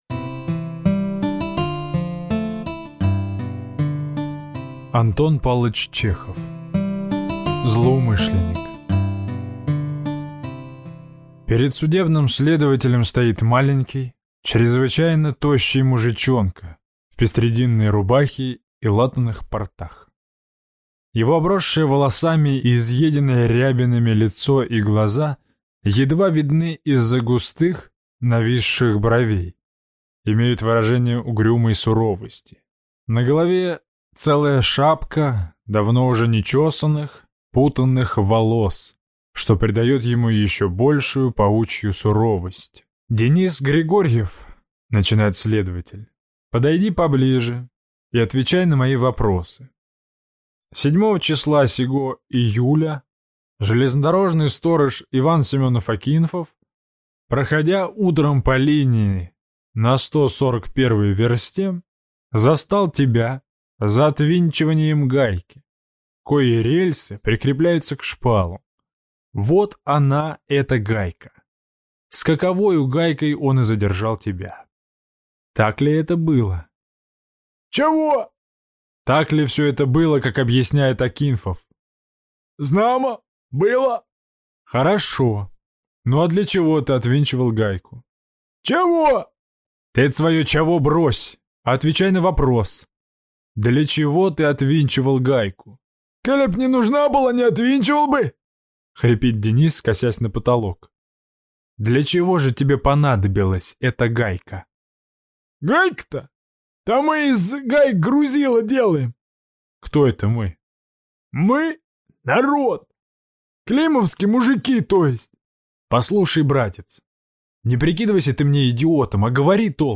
Злоумышленник - аудио рассказ Чехова А.П. Действие рассказа происходит в России во второй половине 19 века в кабинете следователя...